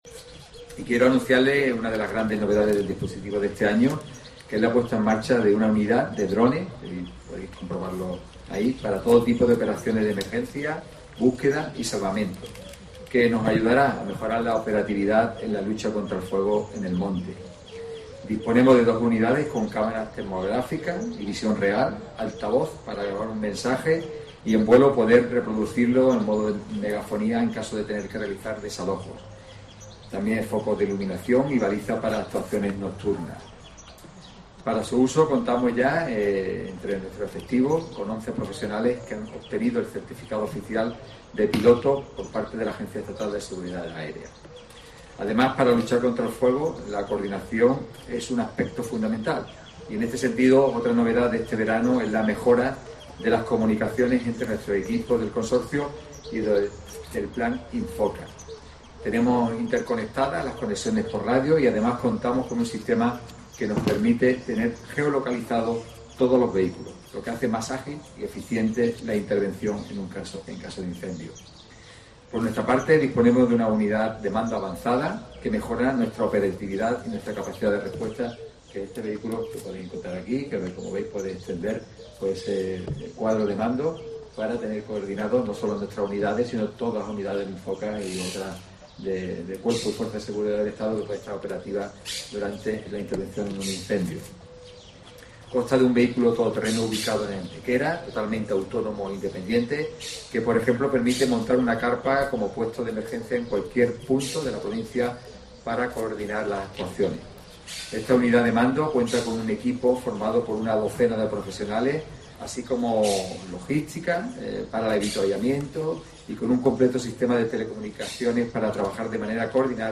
Francisco Salado sobre los nuevos drones del Consorcio Provincial de Bomberos.